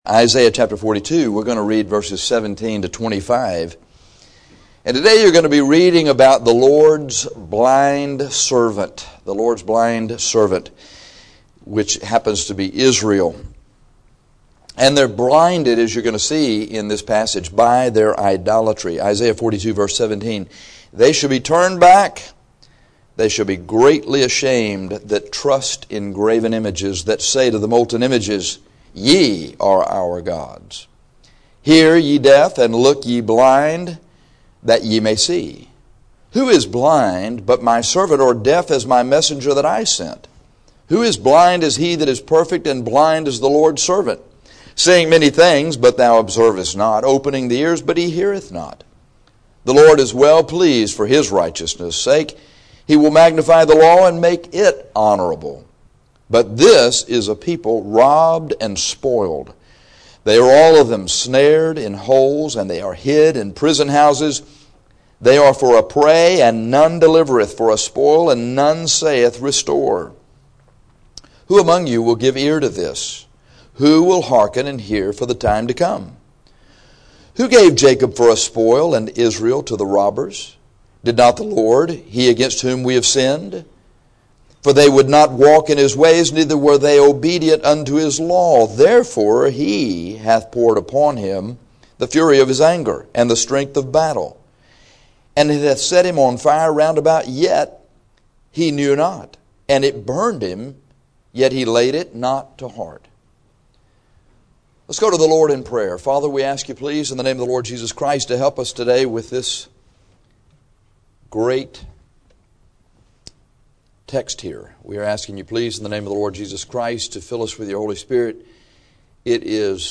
The Lord’s Blind Servant Is. 42:17-25 CLICK TITLE FOR AUDIO * The last few minutes were cut off.